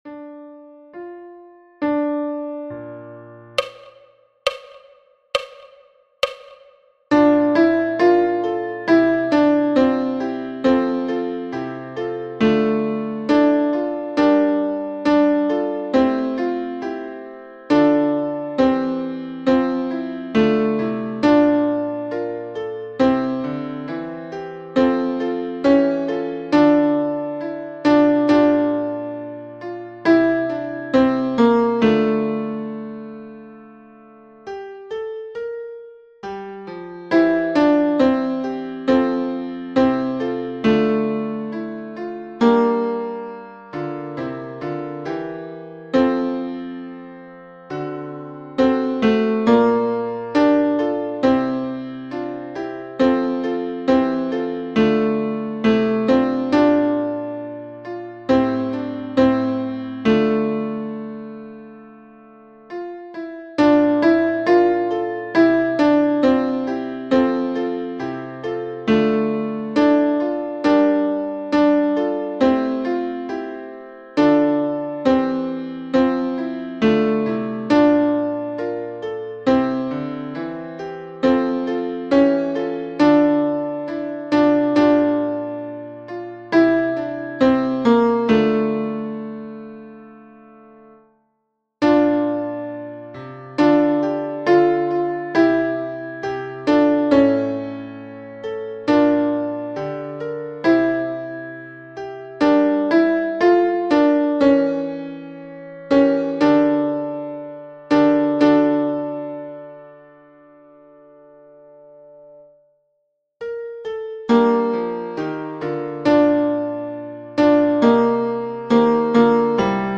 night-tenor.mp3